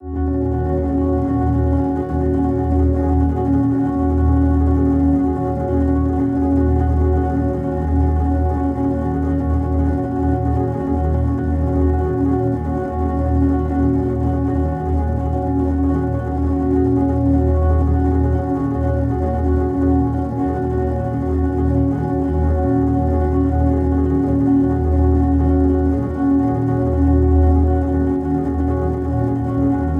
Kagi_FX_Atmos_EbMinMascat_R.wav